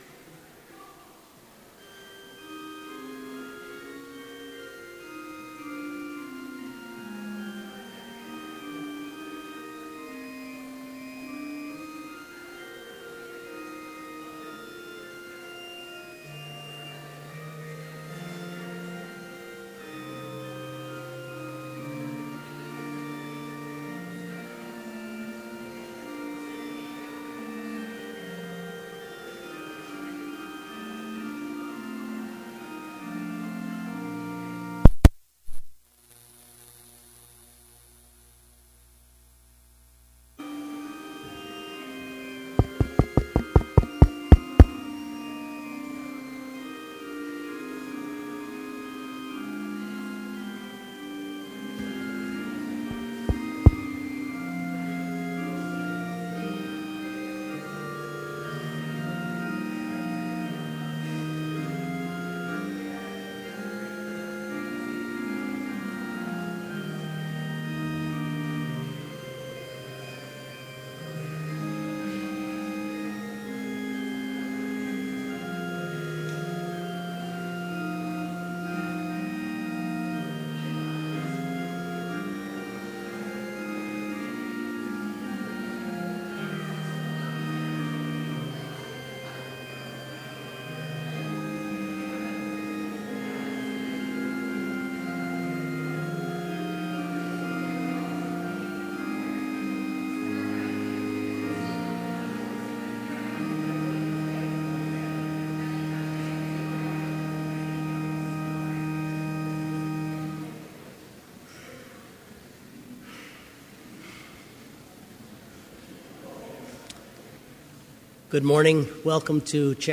Complete service audio for Chapel - May 11, 2016
Prelude
Postlude